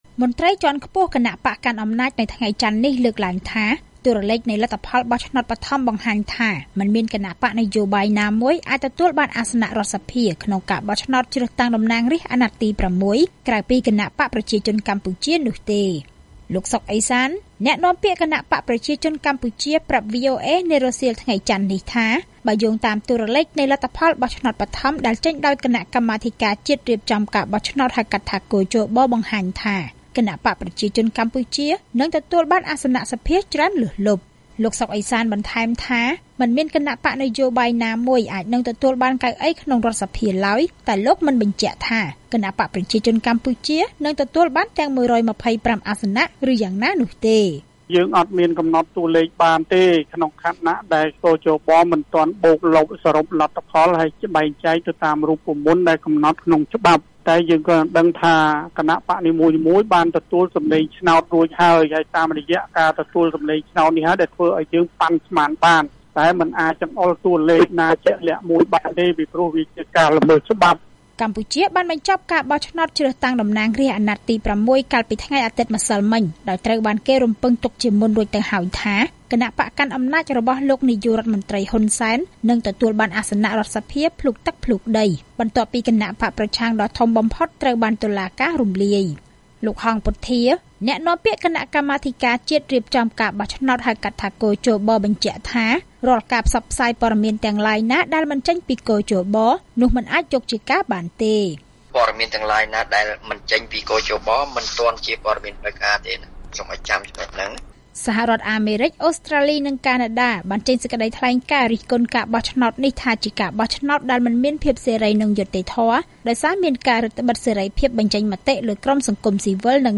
ស្តាប់សេចក្តីរាយការណ៍៖ គណបក្សប្រជាជនកម្ពុជា អាចនឹងទទួលអាសនៈសភាស្ទើរតែទាំងអស់ ក្រោយមានអ្នកបោះឆ្នោតជាង៨០%